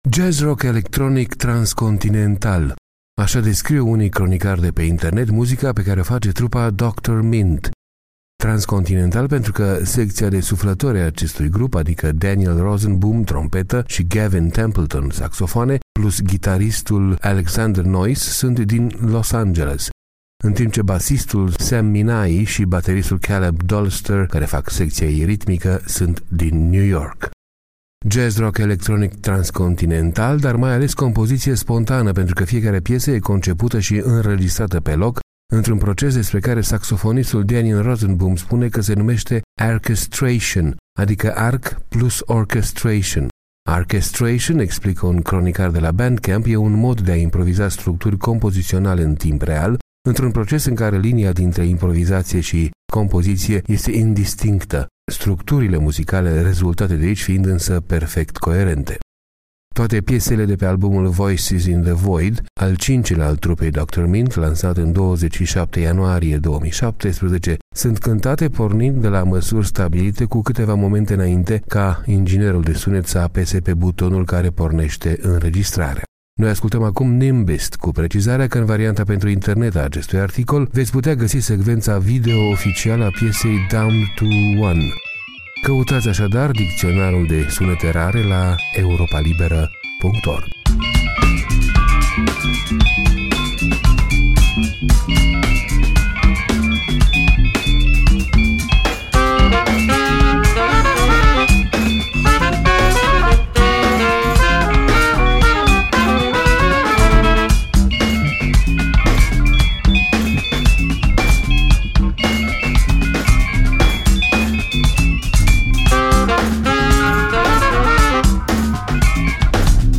O muzică în care linia dintre improvizație și compoziție e indistinctă.